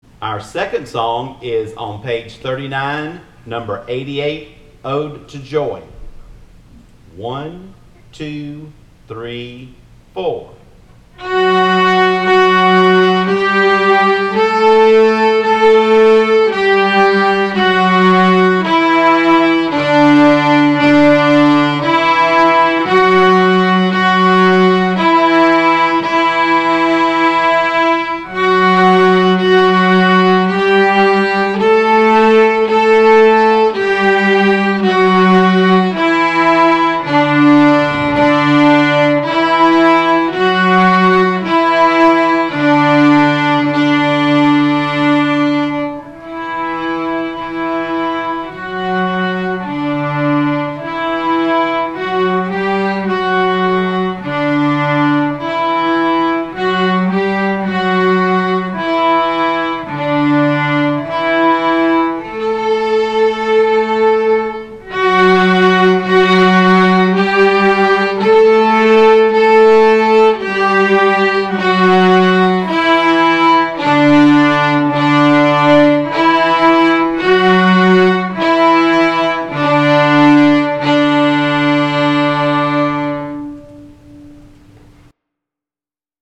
Ode to Joy Violin Ode to Joy Viola Ode to Joy Cello Here are sound clips of your new songs: Ode to Joy Largo from New World Kumbaya HERE IS A BEAUTIFUL PERFORMANCE OF ONE OF MY FAVORITE PIECES.
Orchestra